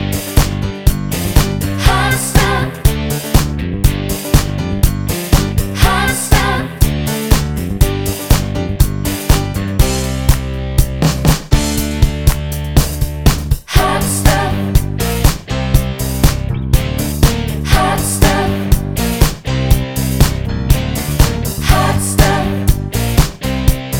Minus Guitar Solo Disco 3:54 Buy £1.50